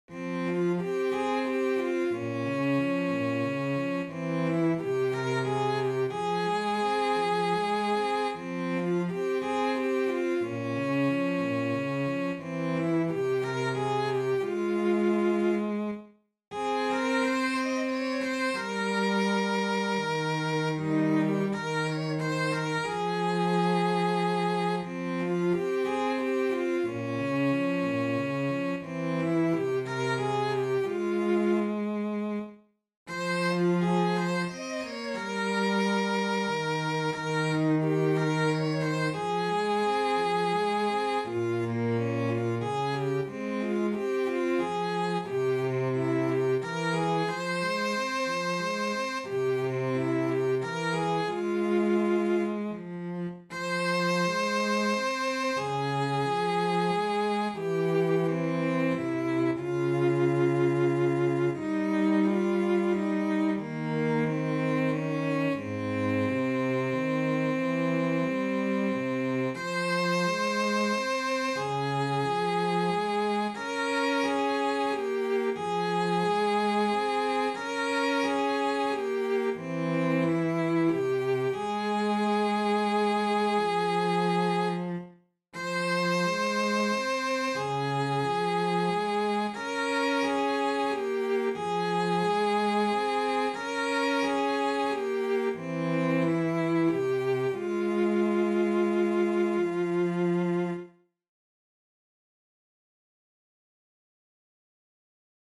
Alla-kuun-ja-auringon-sellot.mp3